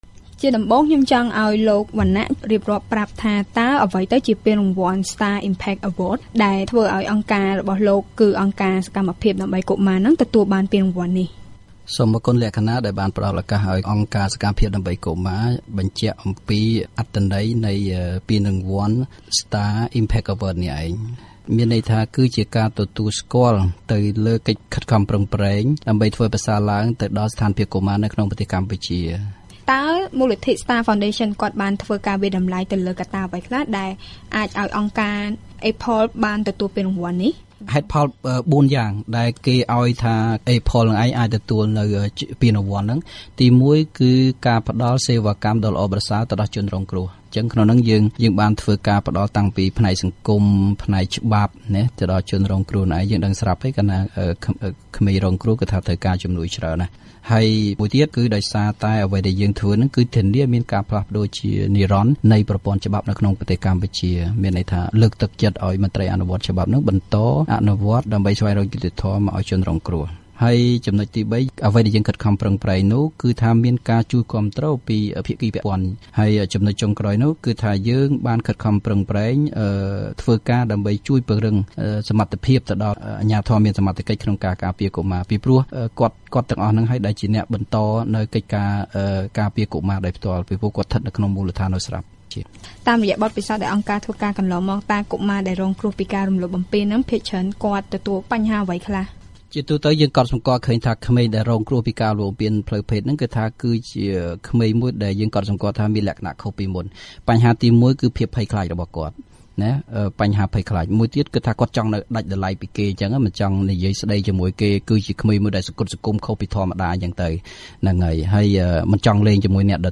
បទសម្ភាសន៍ VOA៖ អង្គការជួយកុមាររងគ្រោះបានទទួលរង្វាន់